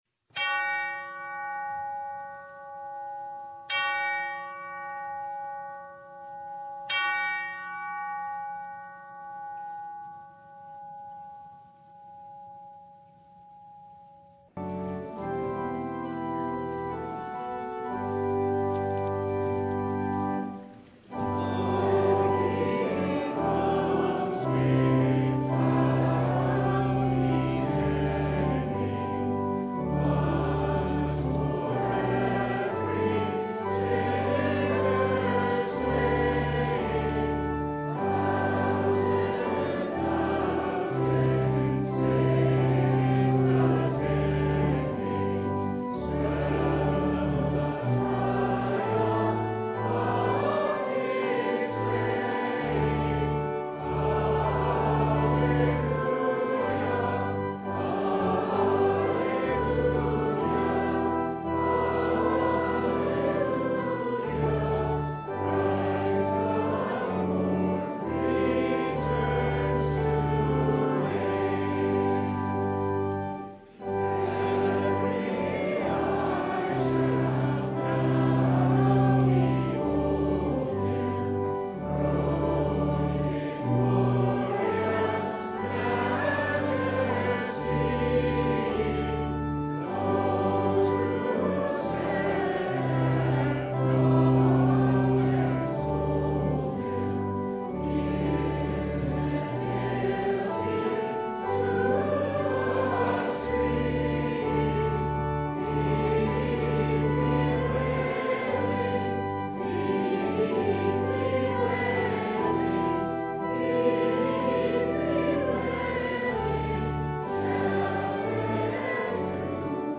Matthew 24:36-44 Service Type: Sunday Advent cries out to us